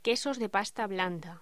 Locución: Quesos de pasta blanda
locución
Sonidos: Voz humana